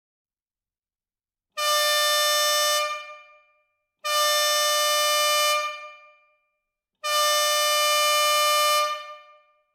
Fisa avertisseur 12V Duet Metal